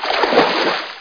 splash1.mp3